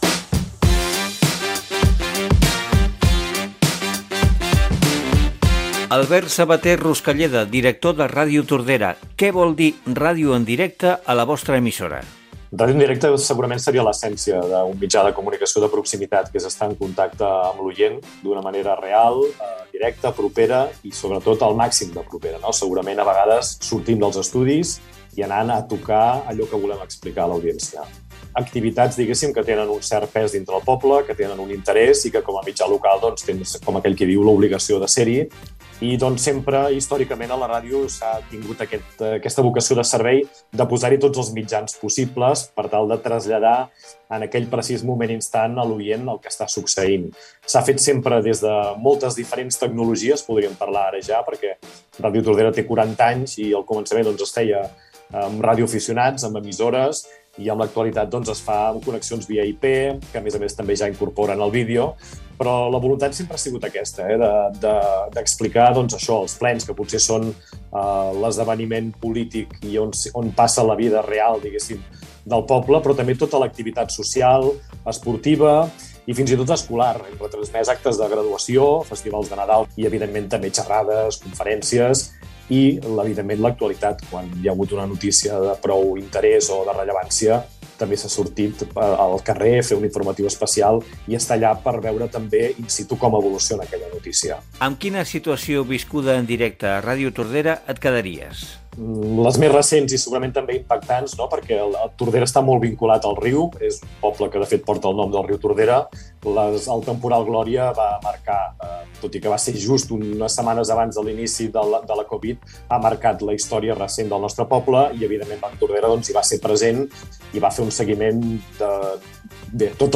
Entreteniment